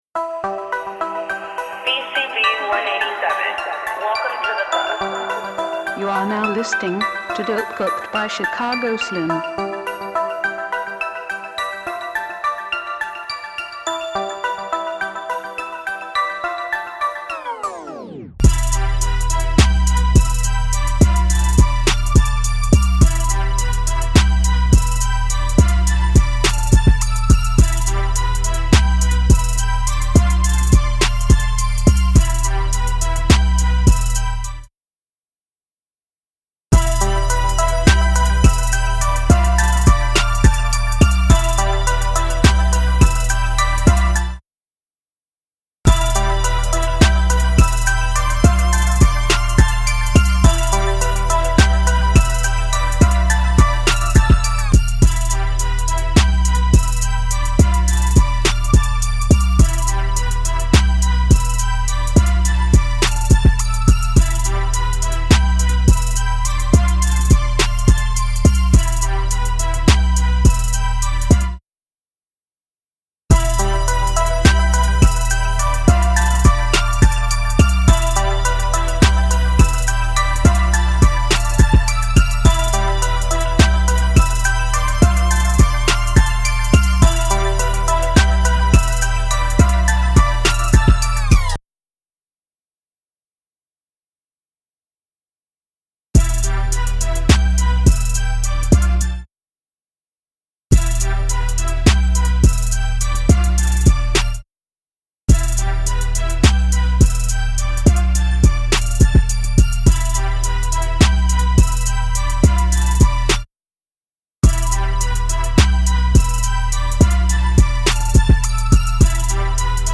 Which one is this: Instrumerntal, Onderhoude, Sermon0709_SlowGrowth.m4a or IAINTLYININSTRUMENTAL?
IAINTLYININSTRUMENTAL